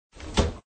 SOpenCabinet.ogg